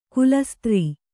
♪ kulastrī